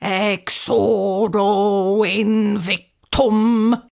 mandrake foundry13data/Data/modules/psfx/library/incantations/older-female/fire-spells/exuro-invictum
exuro-invictum-slow.ogg